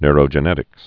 (nrō-jə-nĕtĭks, nyr-)